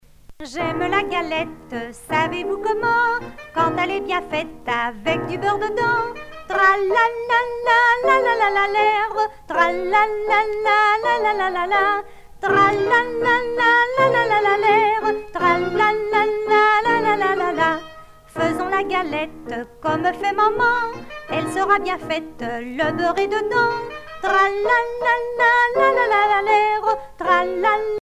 Couplets à danser
danse : ronde
Pièce musicale éditée